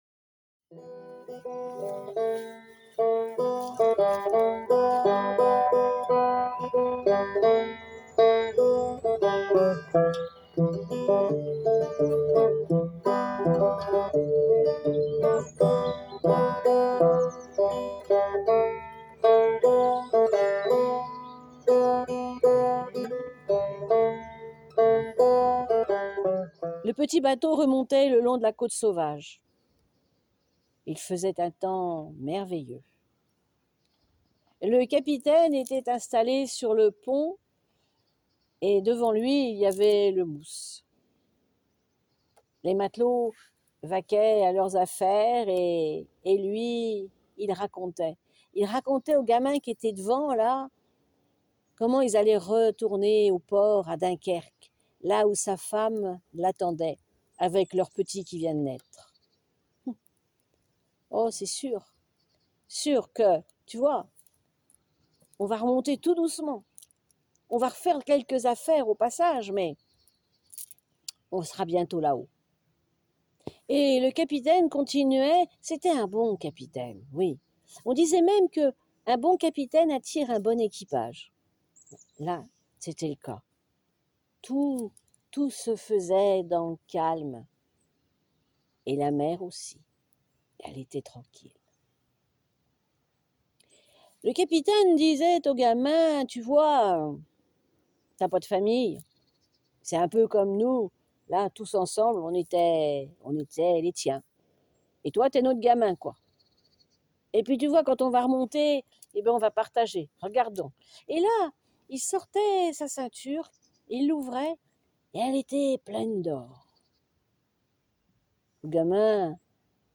L’association Histoires de mots vous propose 3 contes audio pour une immersion totale dans les paysages vécus et contés de la presqu’île de Quiberon.